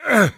attack1.ogg